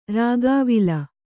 ♪ rāgāvila